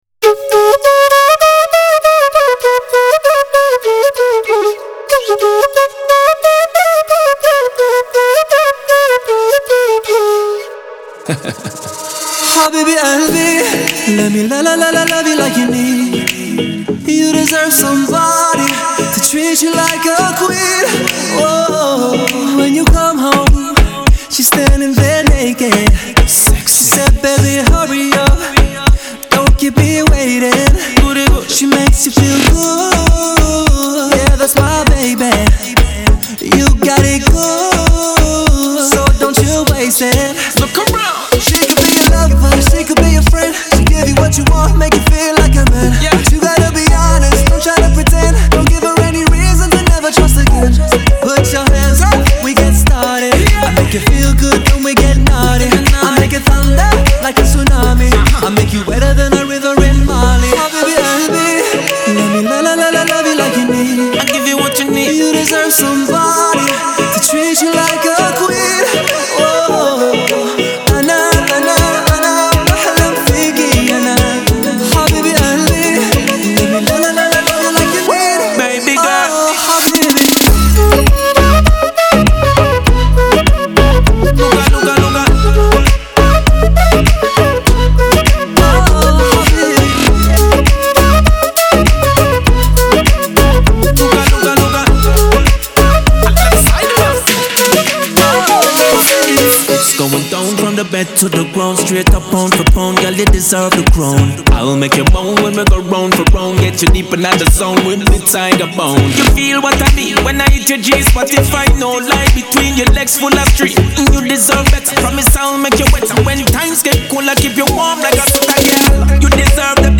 это зажигательный трек в жанре поп и реггетон